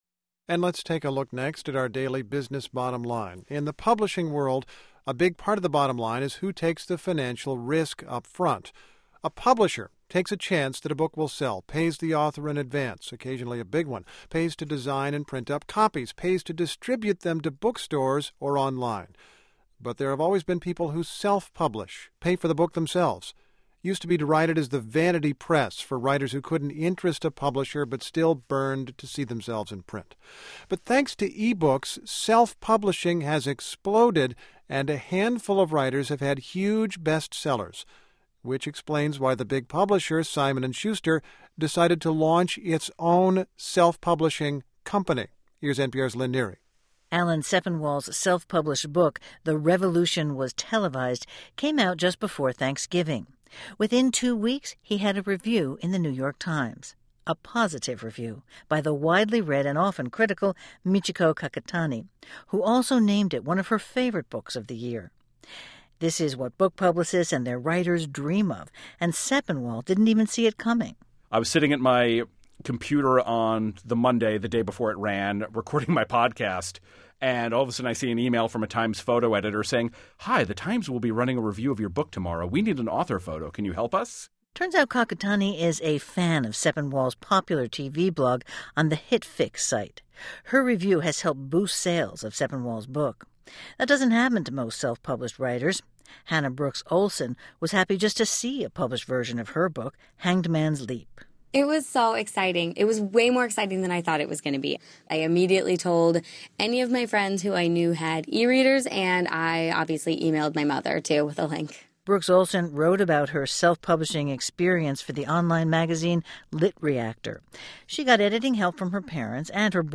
The guest list for this (trust us!) unforgettable night of music and tall tales included Kishi Bashi, Dan Deacon, Carrie Brownstein and Nellie McKay. We hosted the festivities at a secluded cabin we rented one weekend in a snowy woods. It was a chilly night, but we had a roaring fire, plenty of eggnog, vegan haggis and lots of holiday songs to keep everybody warm!